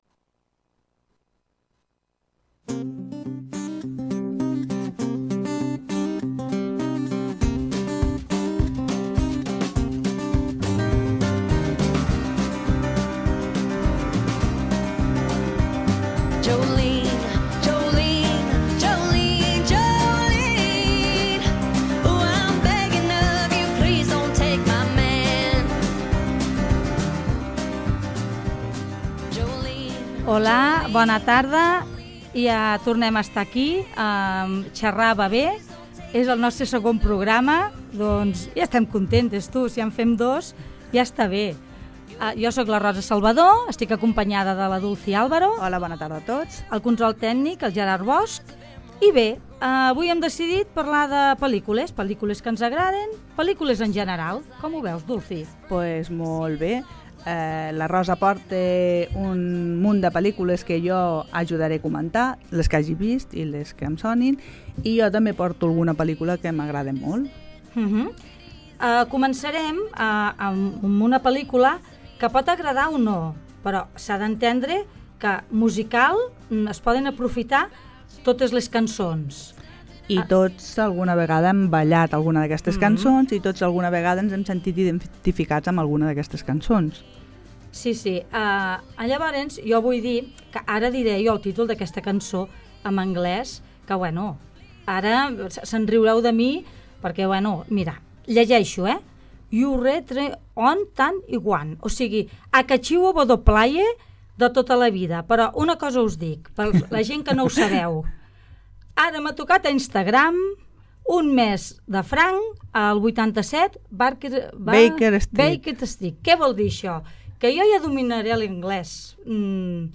Segon programa de «Xerrar va bé» que dediquem a parlar sobre pel·lícules i sèries. Entre la conversa, ens servim de la música per avançar i endinsar-nos en l’ambient dels films.